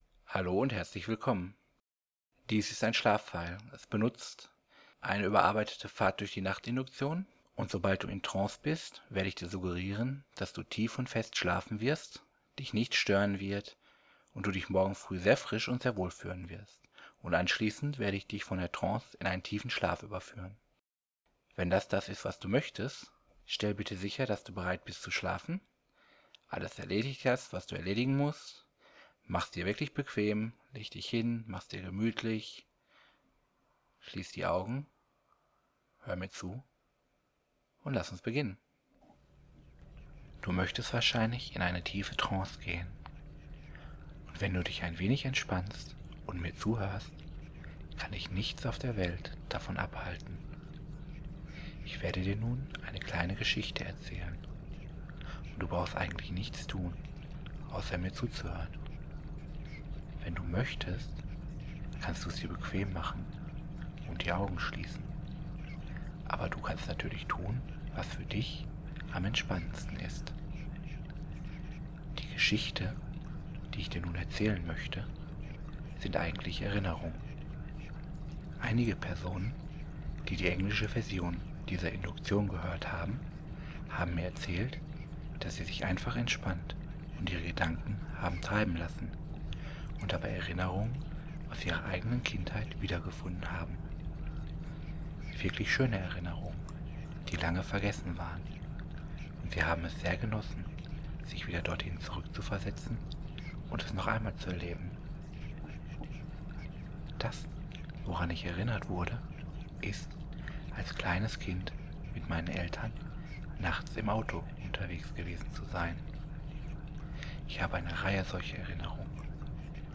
Es gibt zwei Versionen von dieser Datei: die eine nutzt Motorengeräusche als Hintergrund, die andere einen binauralen Ton, der dir ebenfalls beim Einschlafen helfen soll.
Schlaf (mit Motorgeräuschen) Schlaf (mit Binaural) You like what you hear?
Schlaf_Motor.mp3